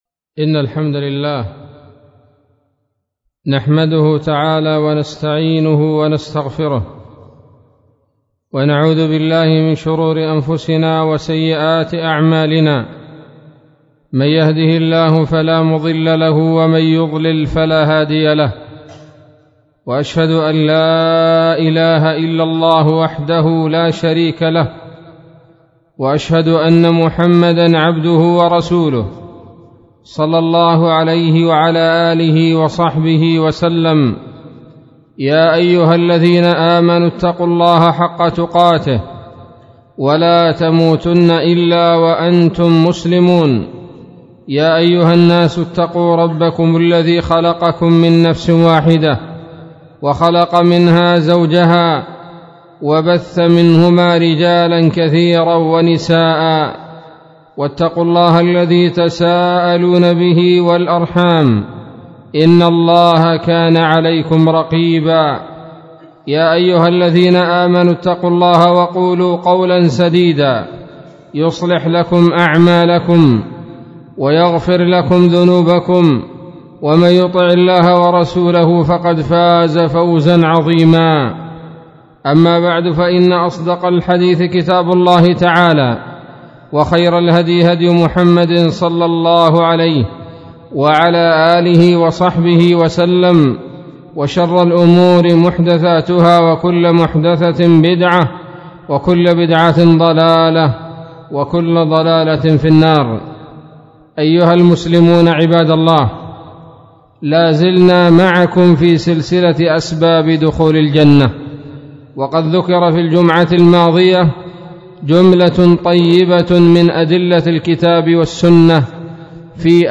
خطبة بعنوان : ((تمام المنة في ذكر بعض أسباب دخول الجنة [2])) 3 ربيع الأول 1438 هـ